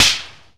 NY METAL 2.wav